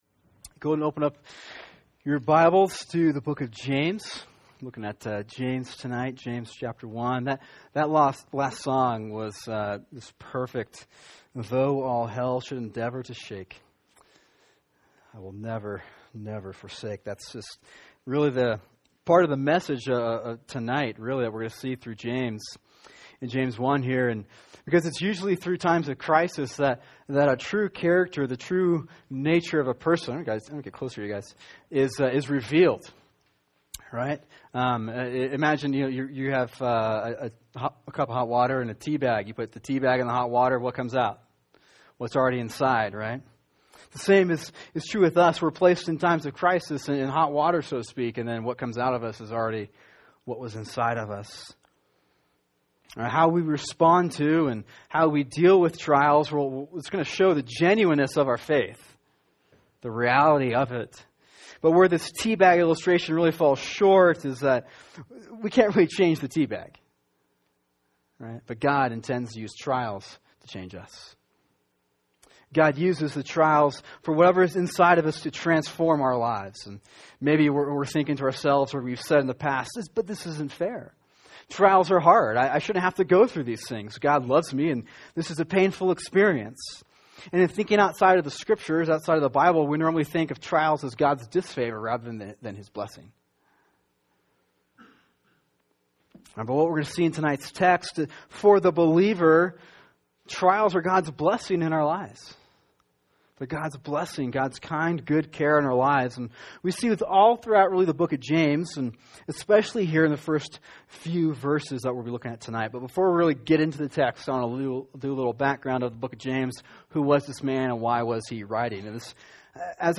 [sermon] James 1:2-4 “Faith Tested by Trials” | Cornerstone Church - Jackson Hole